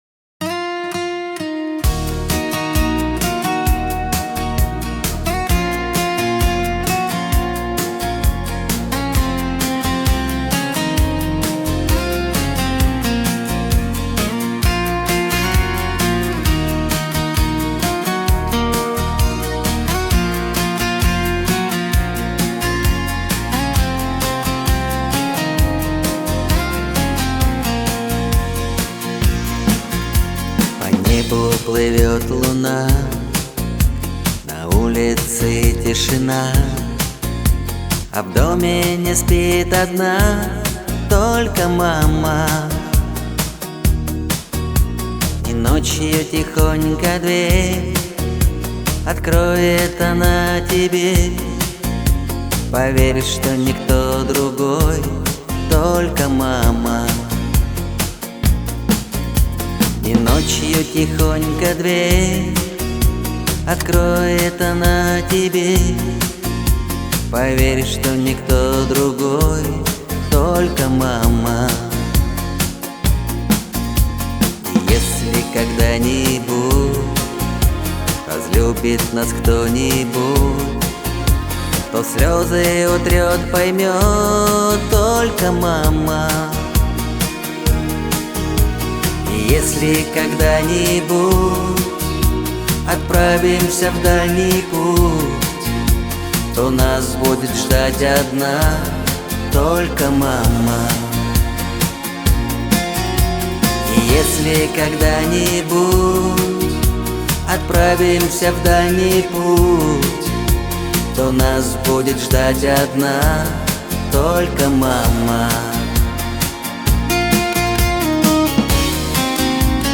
Качество: 320 kbps, stereo
Поп про любовь